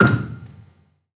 Gambang-F5-f.wav